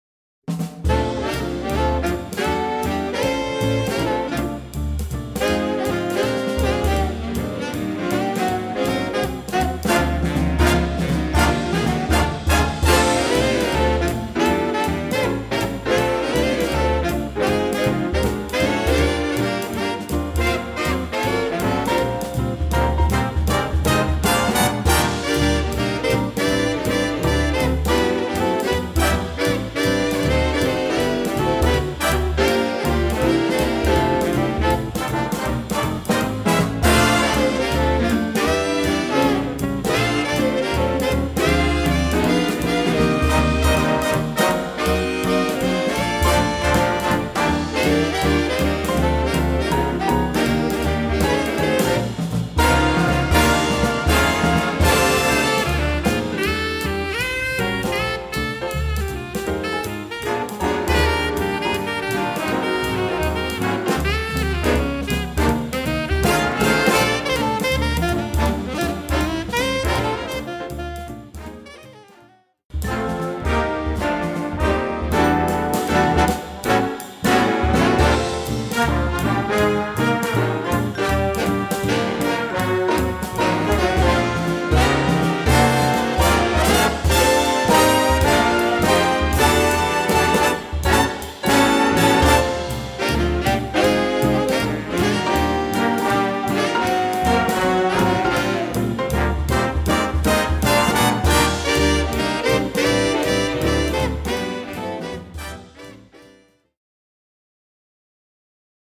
トロンボーン・セクション・フィーチャー